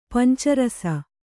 ♪ panca rasa